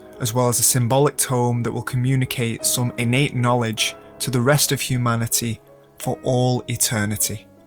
Vocal Sample